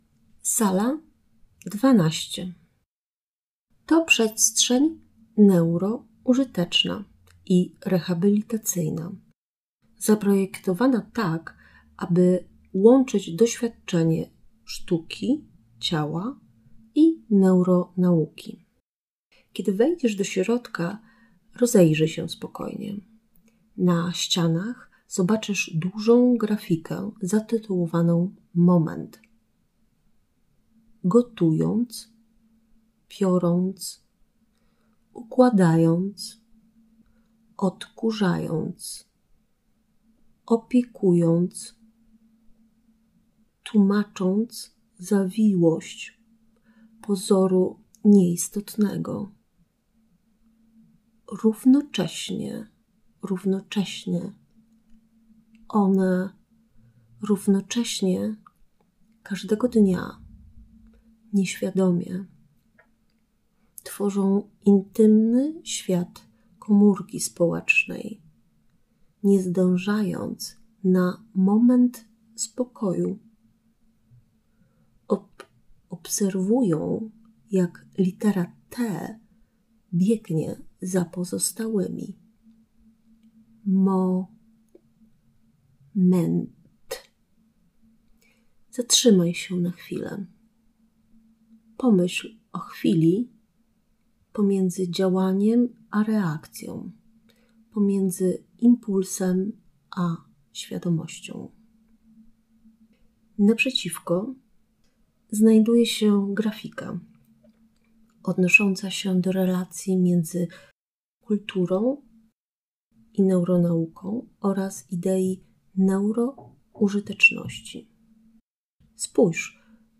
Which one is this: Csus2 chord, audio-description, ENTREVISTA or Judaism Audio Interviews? audio-description